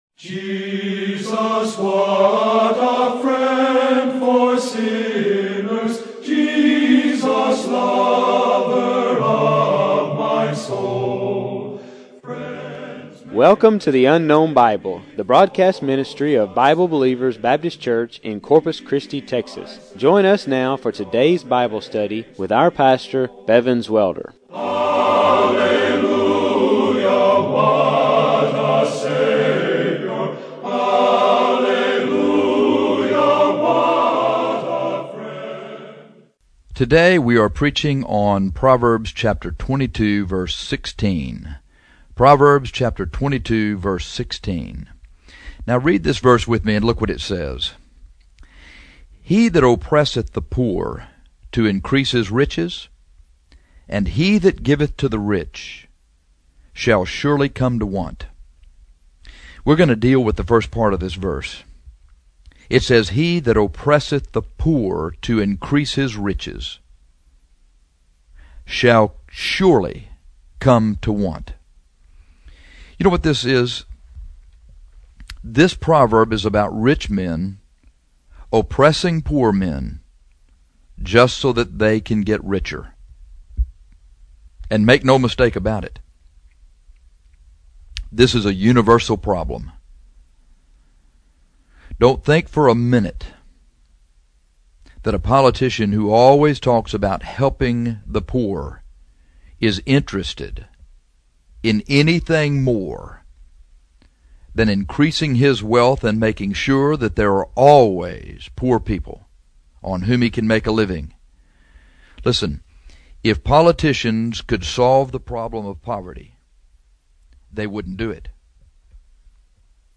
There are many rich men who have increased their wealth by oppressing the poor. This broadcast deals with some Biblical examples of men who have done this.